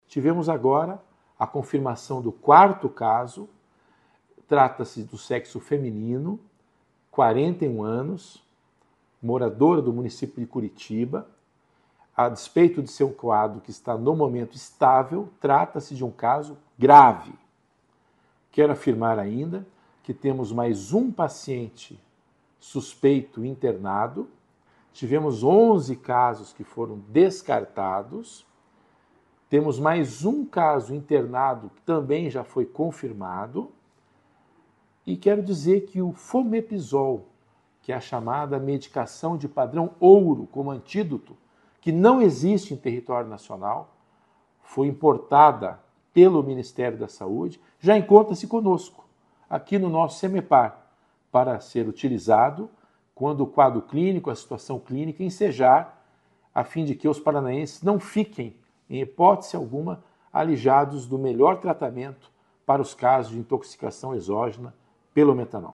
Ouça o que diz o secretário de Saíde do Paraná em exercício, César Neves: